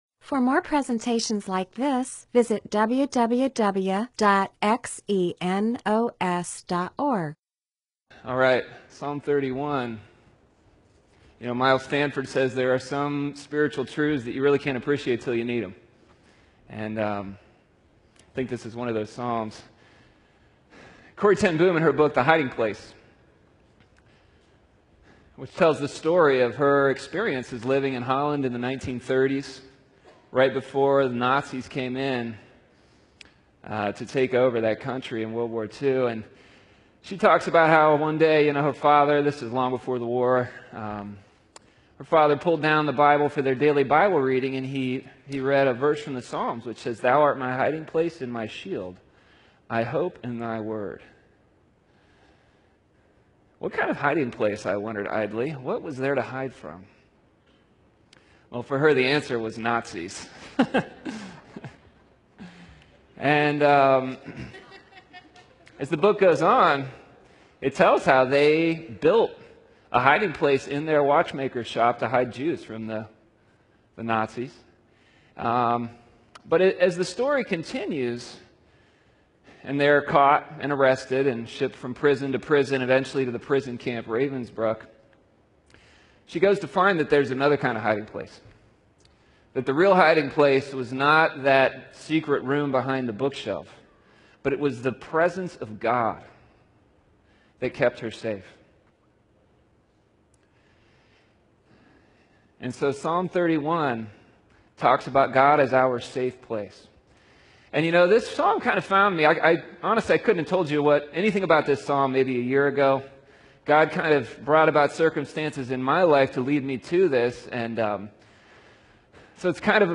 MP4/M4A audio recording of a Bible teaching/sermon/presentation about Psalms 31.